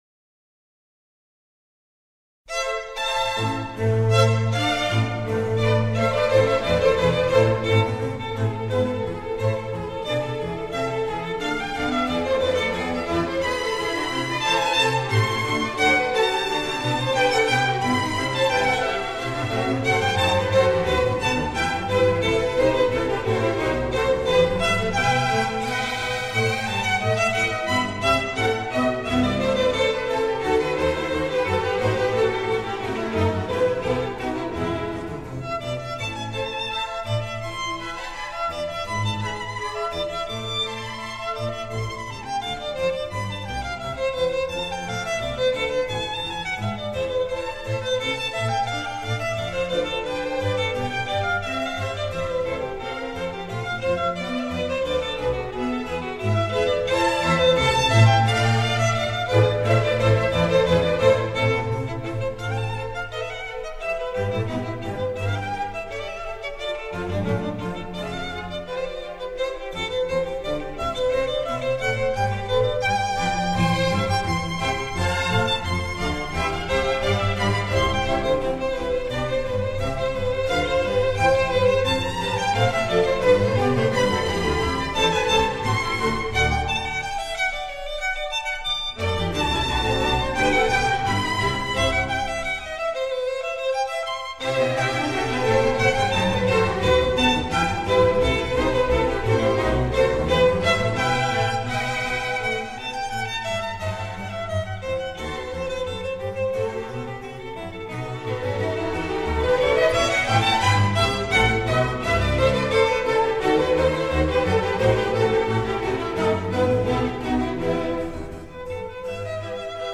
Violin concerto